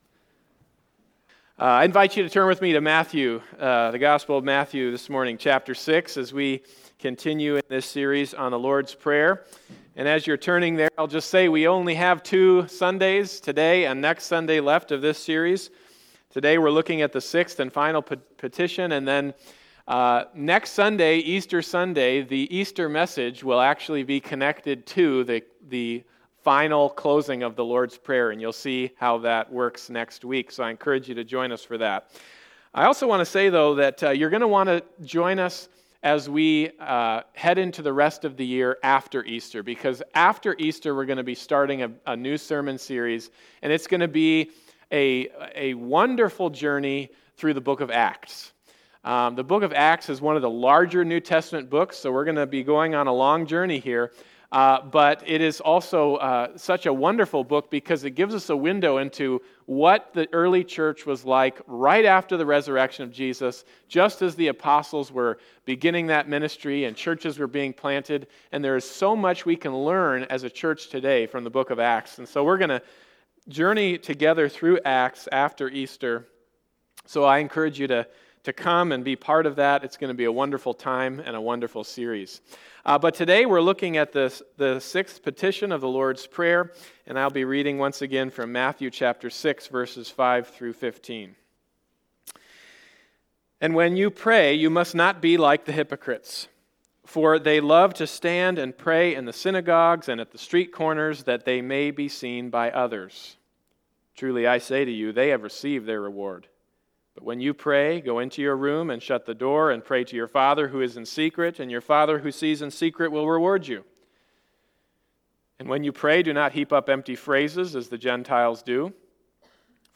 Matthew 6:5-15 Service Type: Sunday Morning Service « Forgive Us Our Debts The Kingdom